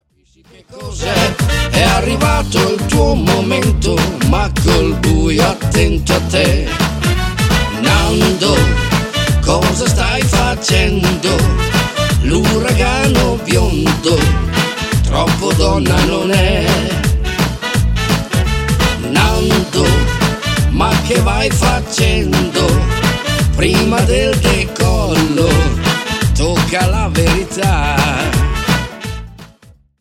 MAMBO  (4.42)